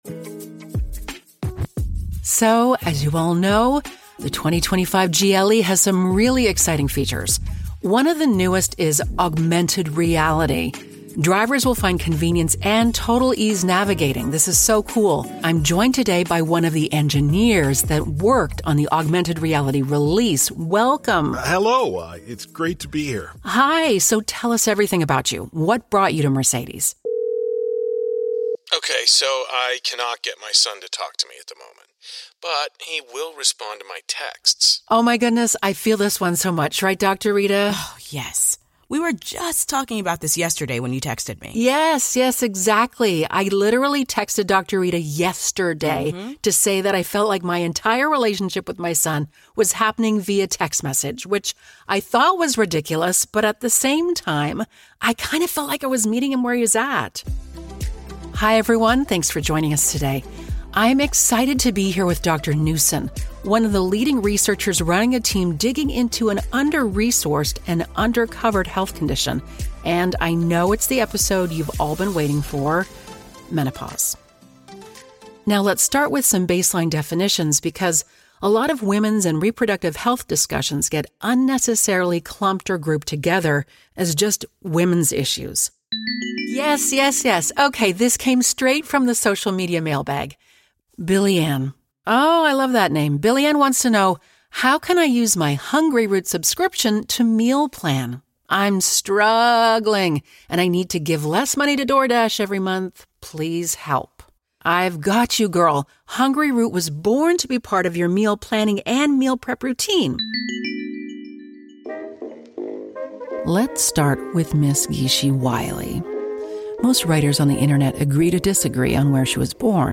Englisch (US)
Eine warme, fürsorgliche Stimme für mitfühlende Werbespots.
Mit einer StudioBricks-Kabine und einem Sennheiser-Mikrofon produziere ich effektiv Audio in Studioqualität.